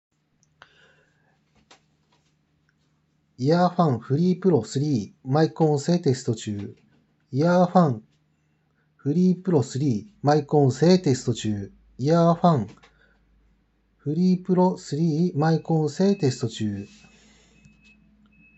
マイク性能はそれなり
周辺音も拾うけど音声もハッキリ聞こえるレベルに作られています。
✅静かな環境